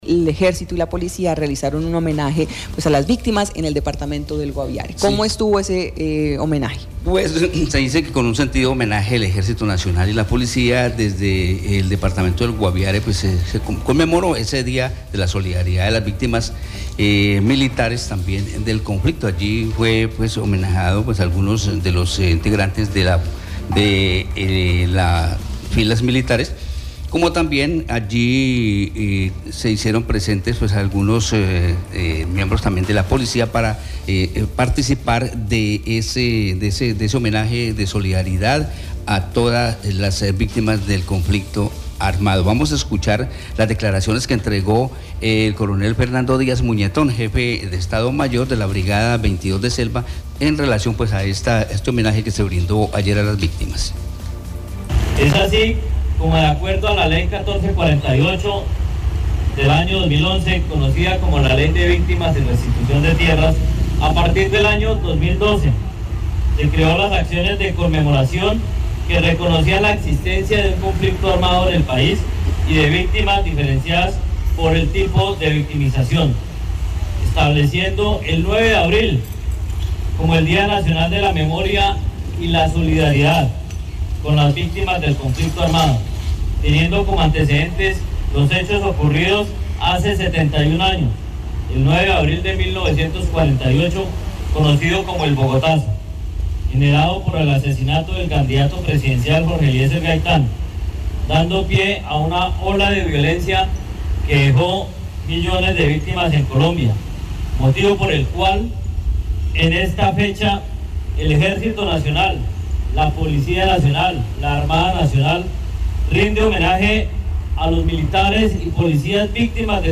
La Ceremonia se cumplió en el Parque de la Vida, en San José del Guaviare, donde se realizó una completa demostración de fe y acompañamiento ante esta situación.